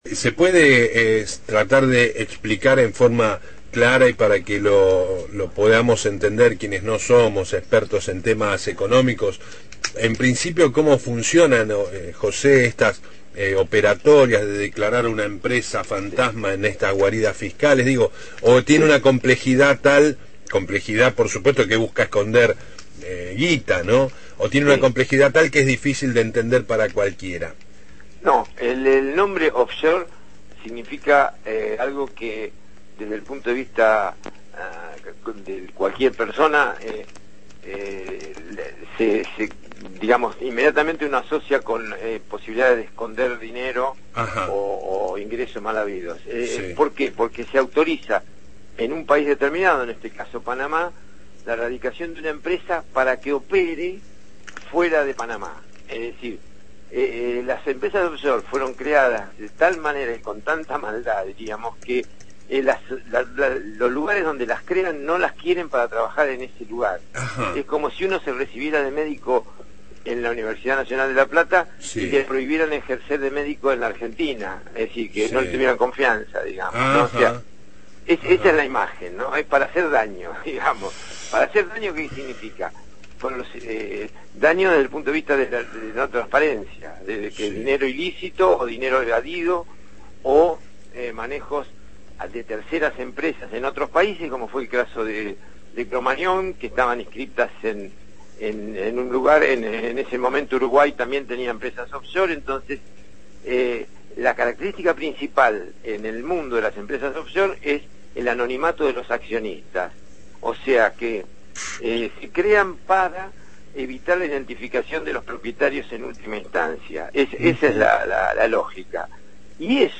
economista especializado en temas fiscales y tributarios y ex titular de la Unidad de Información Financiera (UIF)